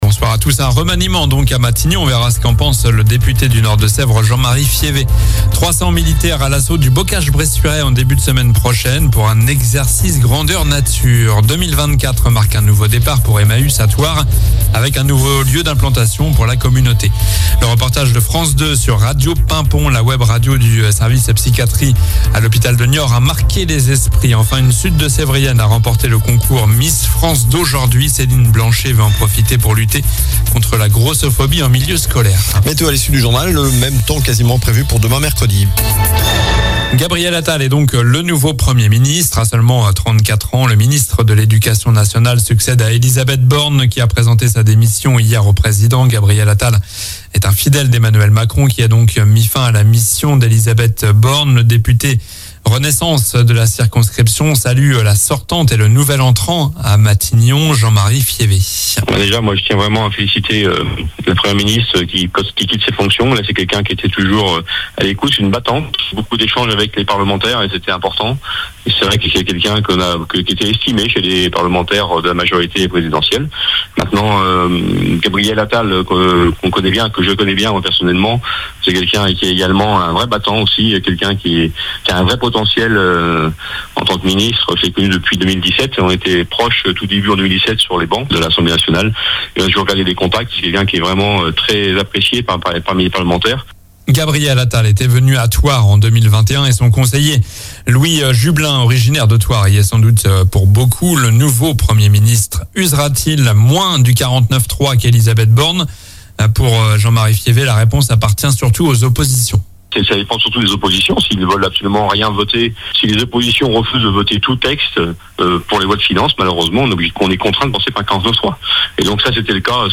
Journal du mardi 09 janvier (soir)